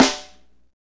SNR FNK S08L.wav